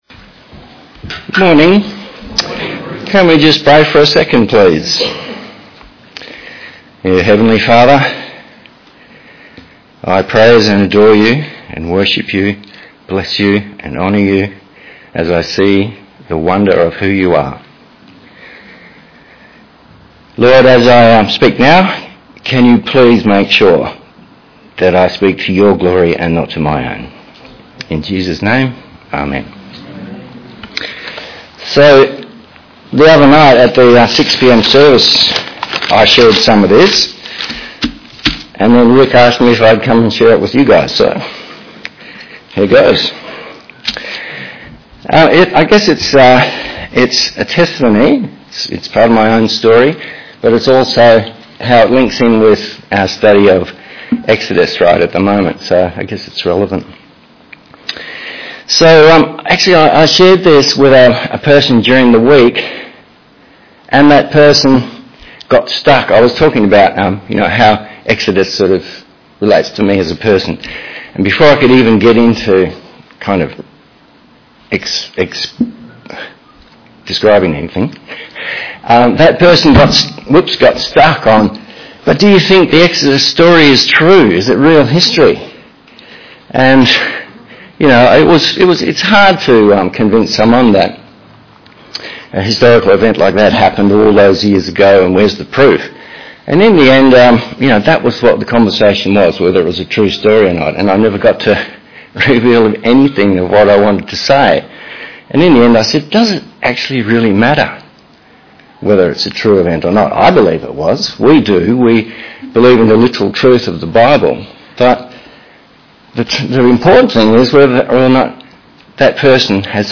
Series: Testimony
Service Type: Sunday AM